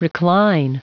Prononciation du mot recline en anglais (fichier audio)
Prononciation du mot : recline